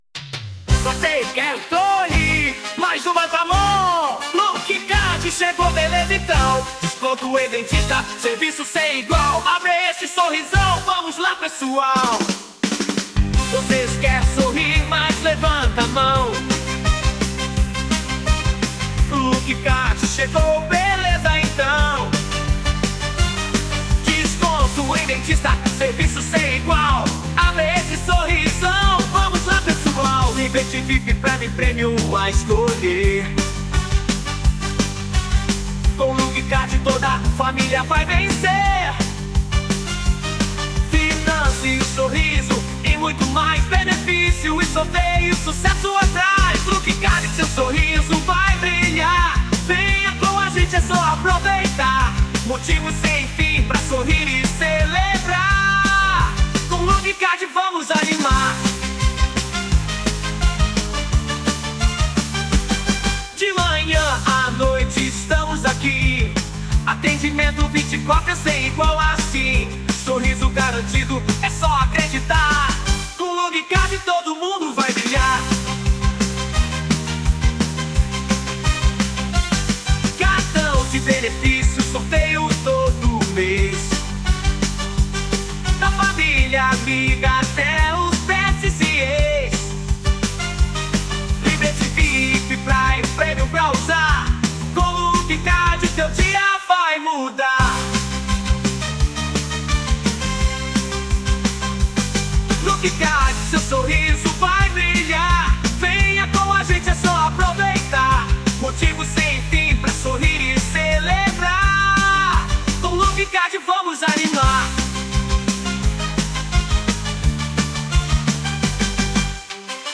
Músicas para Marketing